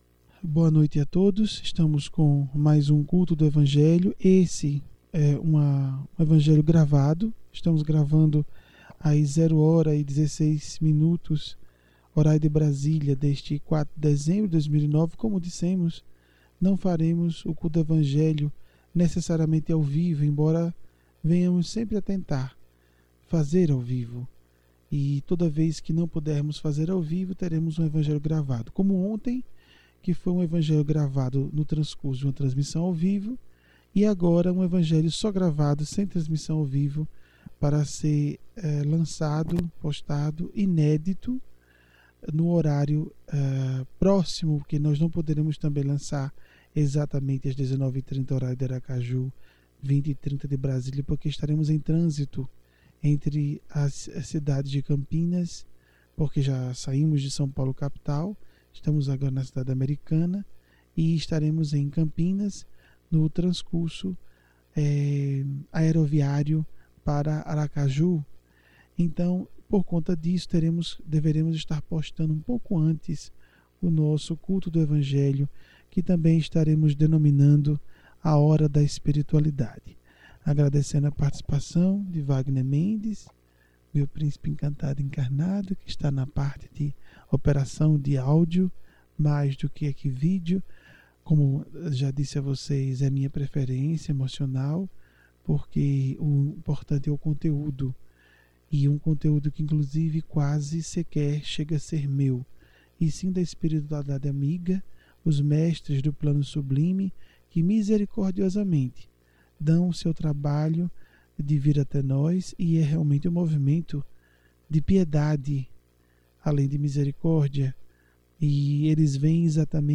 Palestras memoráveis e práticas do Evangelho